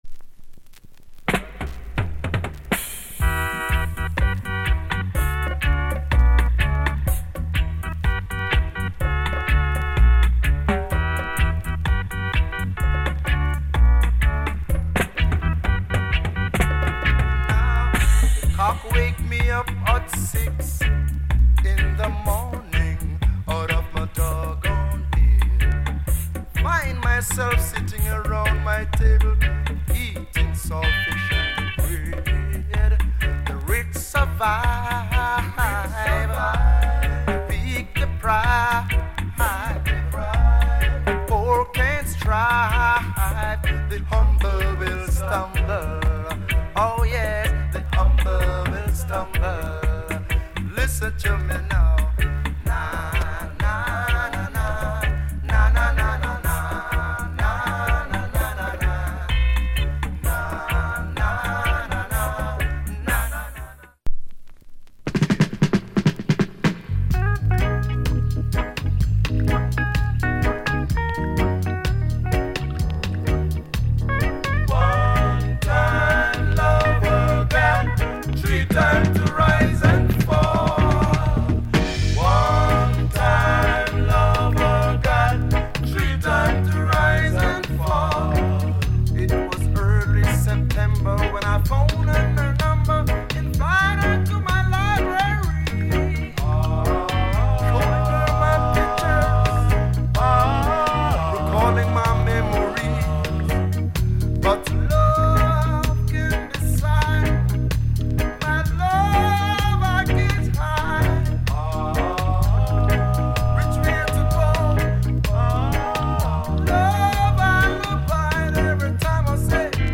Genre Roots Rock / Male Vocal